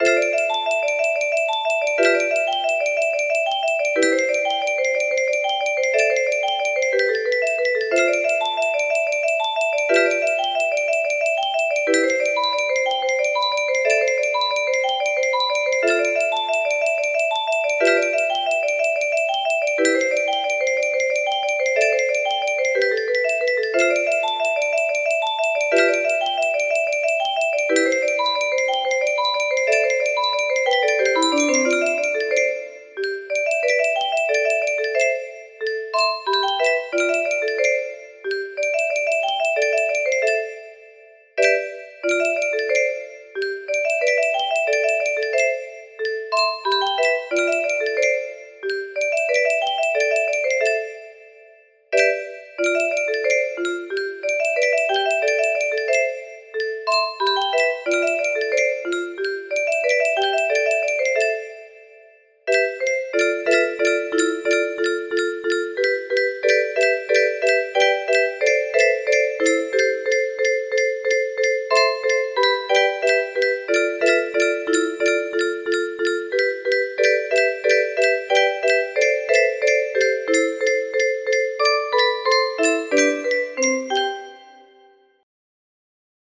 Grand Illusions 30 (F scale)
BPM 91